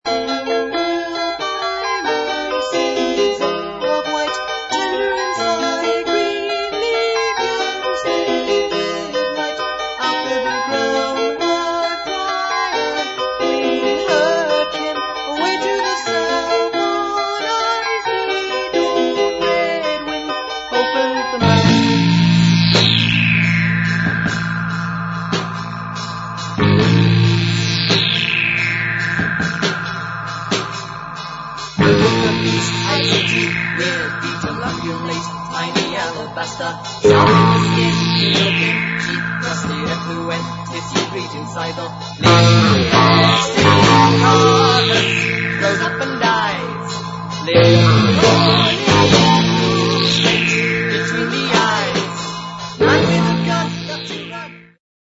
We taped the results as we went along.
Clips (mp3 medium quality stereo)